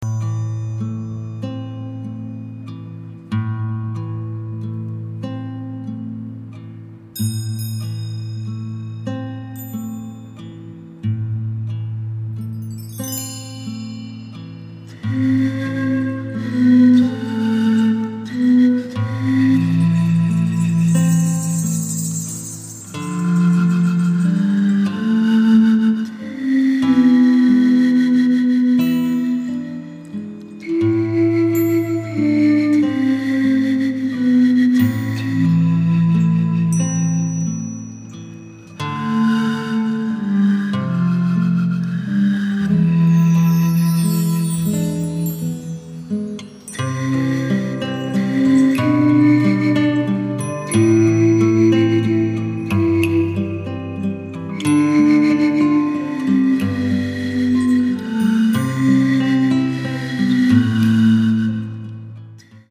at studio Voice
ビン笛、フルート、ギター